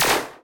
poly_shoot_laser03.wav